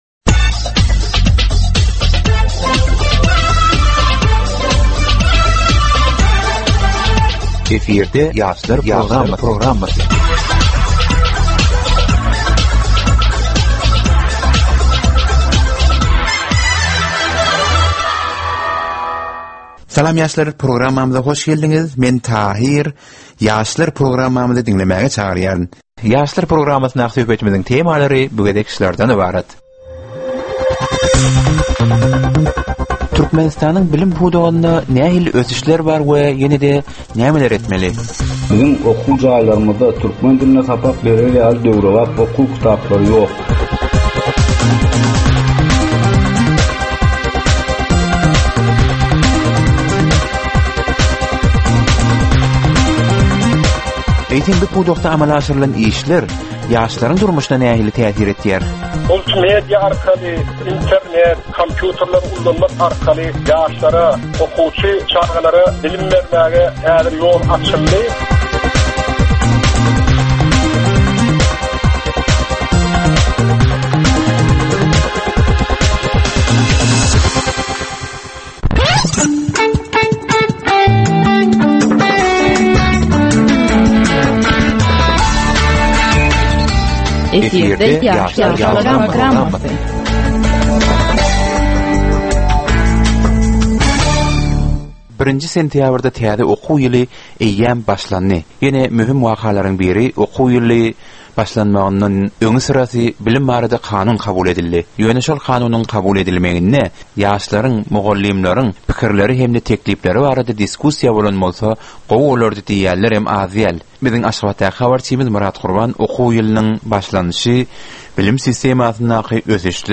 Türkmen we halkara yaşlarynyň durmuşyna degişli derwaýys meselelere we täzeliklere bagyşlanylyp taýýarlanylýan 15 minutlyk ýörite gepleşik. Bu gepleşikde ýaslaryň durmuşyna degişli dürli täzelikler we derwaýys meseleler barada maglumatlar, synlar, bu meseleler boýunça adaty ýaşlaryň, synçylaryň we bilermenleriň pikrileri, teklipleri we diskussiýalary berilýär. Gepleşigiň dowmynda aýdym-sazlar hem eşitdirilýär.